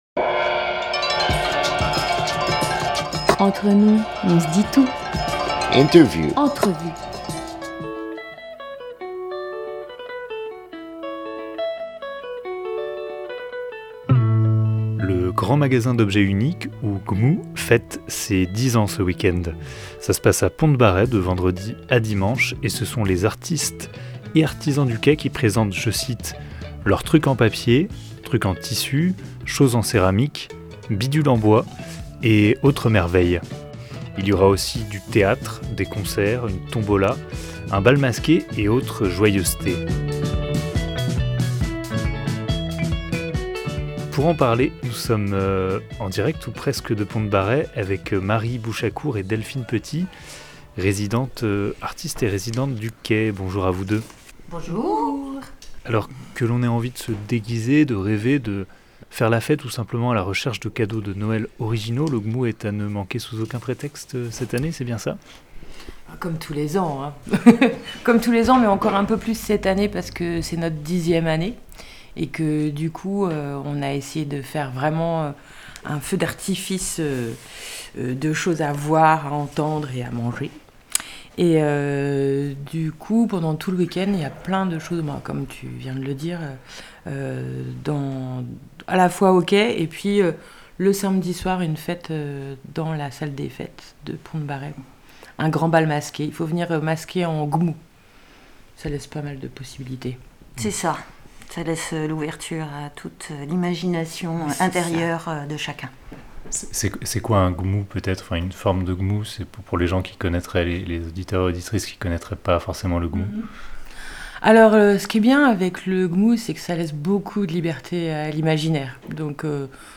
24 novembre 2022 12:13 | Interview